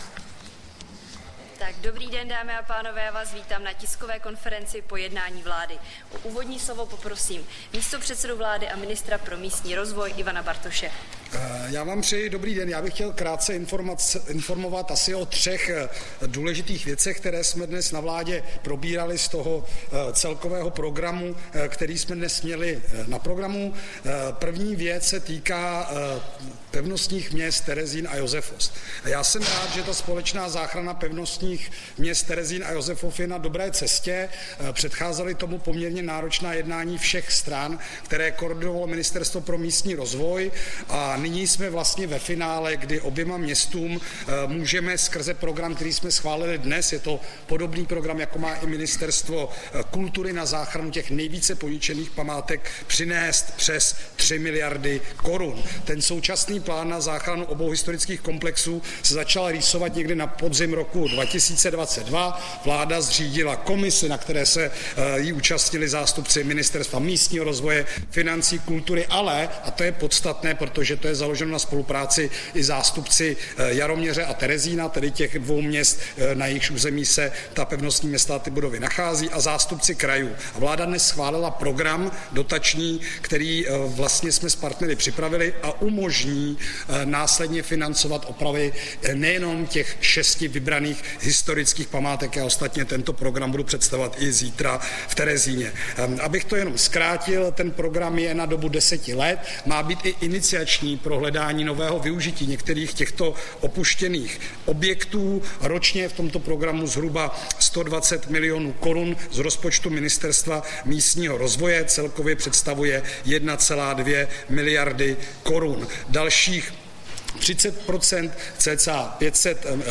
Tisková konference po jednání vlády, 29. května 2024